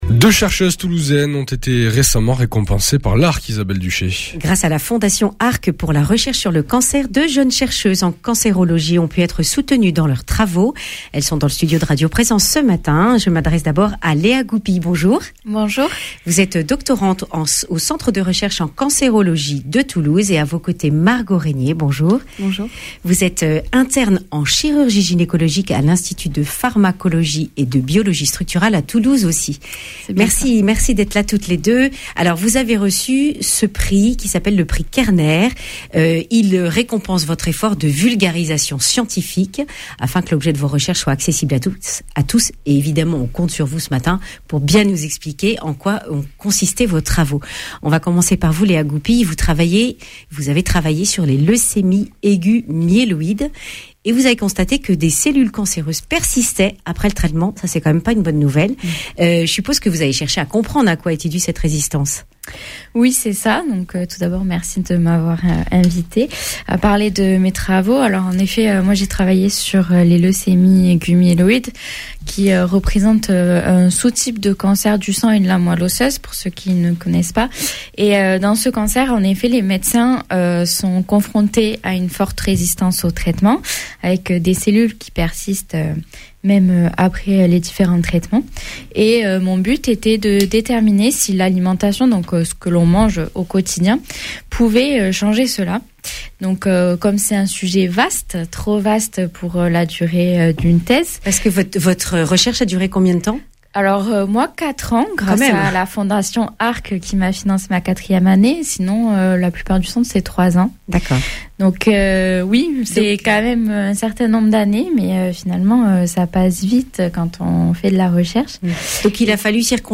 Accueil \ Emissions \ Information \ Régionale \ Le grand entretien \ Deux chercheuses récompensées pour leurs travaux de vulgarisation de leur (…)